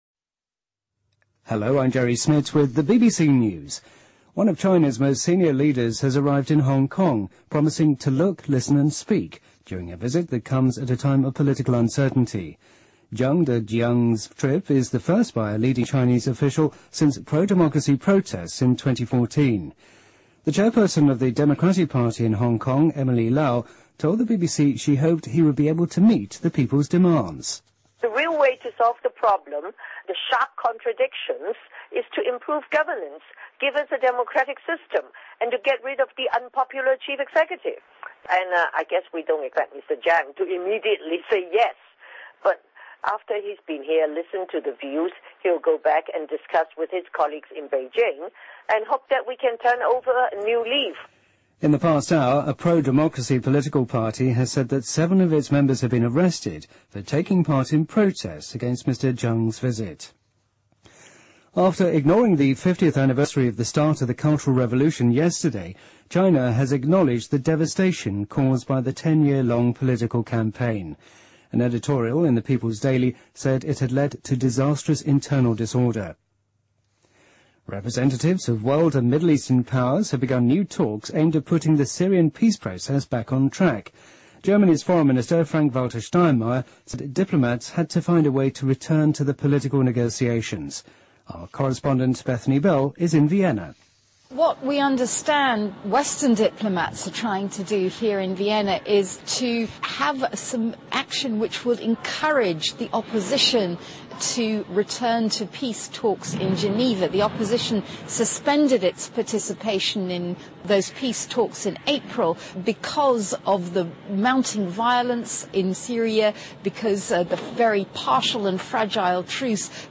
BBC news,美国成功完成首例男性生殖器官移植